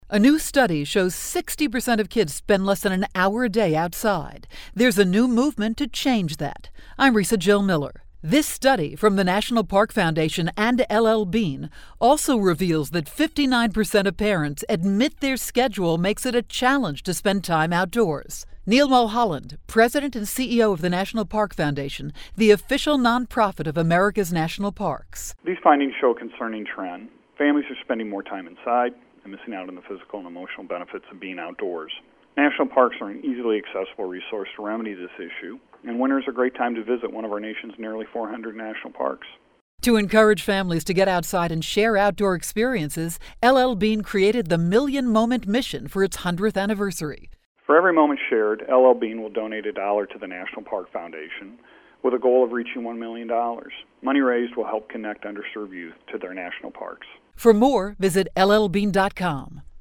January 17, 2012Posted in: Audio News Release